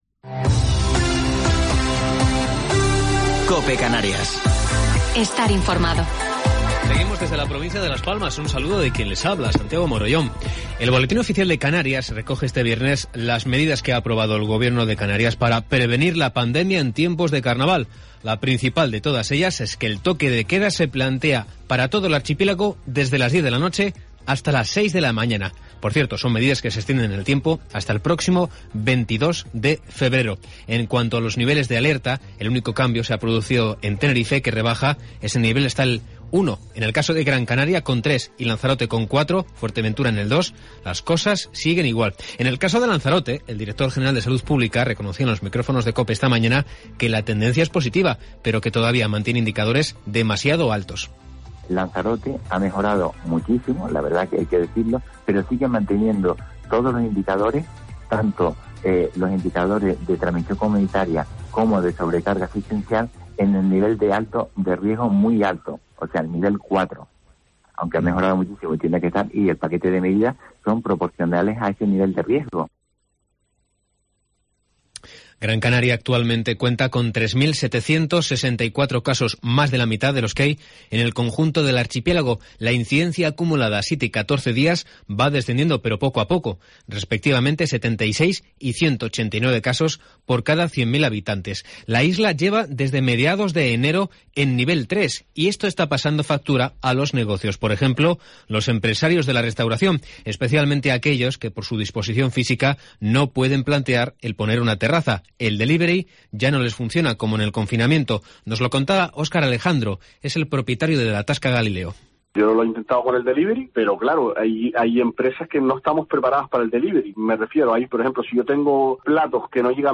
Informativo local 12 de Febrero del 2021